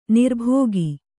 ♪ nirbhōgi